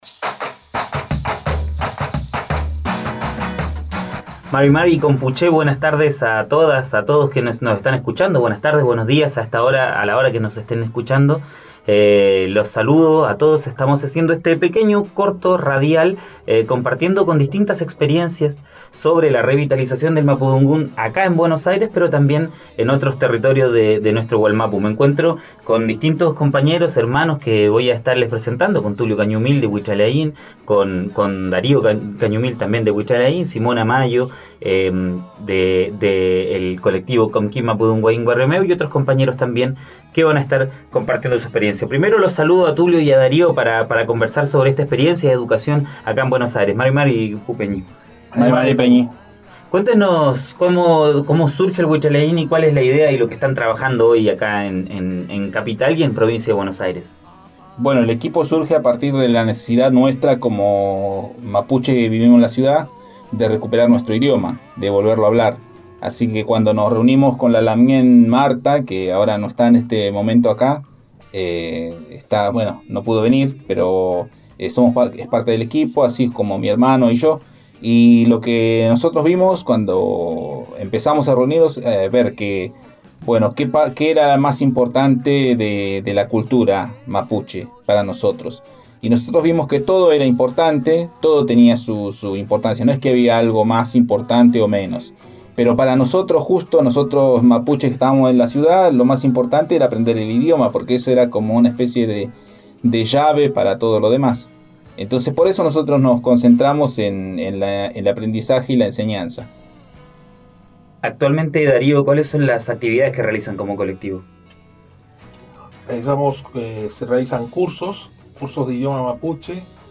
Programa radial Puelmapuexpress: El mapudungun en las ciudades - Mapuexpress